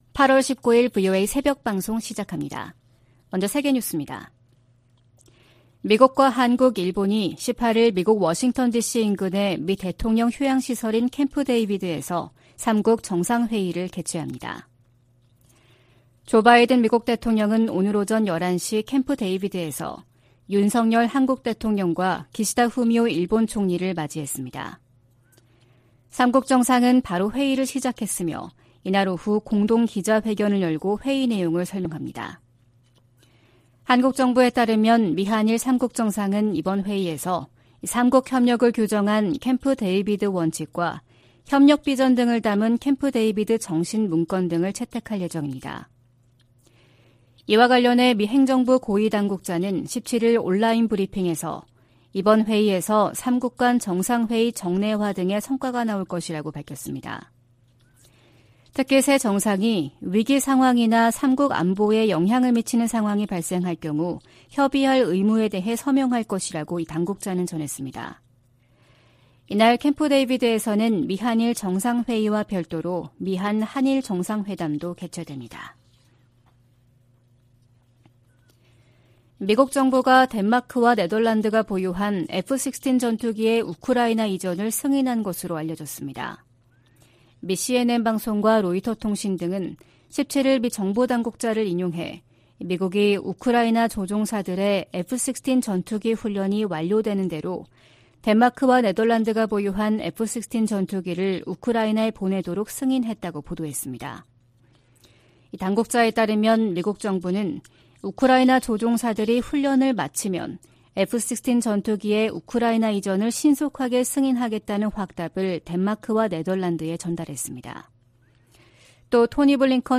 VOA 한국어 '출발 뉴스 쇼', 2023년 8월 19일 방송입니다. 미한일 캠프데이비드 정상회의에서 공동 안보 협약과 회의 정례화 등 역사적인 성과가 나올 것이라고 미국 고위당국자가 밝혔습니다. 6년 만에 열린 유엔 안보리 북한 인권 공개 논의에서 미국 등 52개국이 별도 성명을 발표했습니다. 미국 정부가 유엔 안보리에서 북한과 러시아 간 무기거래 문제를 제기하는 방안을 모색할 것이라고 유엔주재 미국대사가 밝혔습니다.